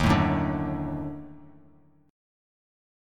D#mM7b5 chord